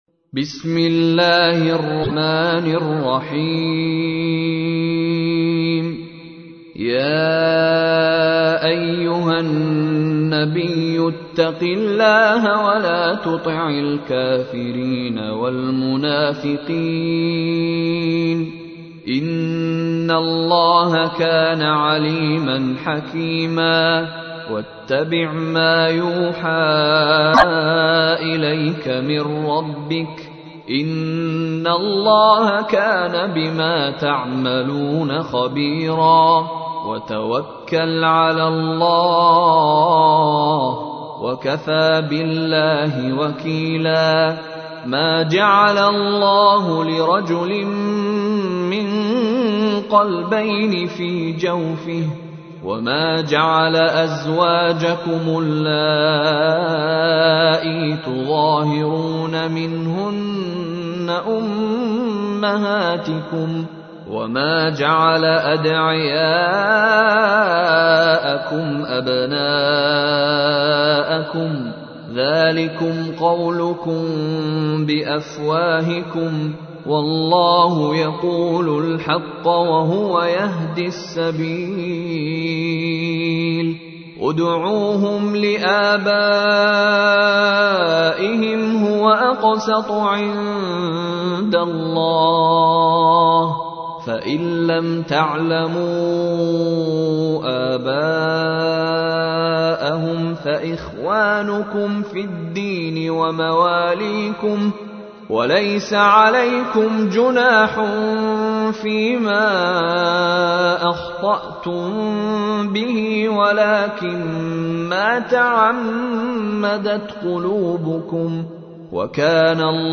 تحميل : 33. سورة الأحزاب / القارئ مشاري راشد العفاسي / القرآن الكريم / موقع يا حسين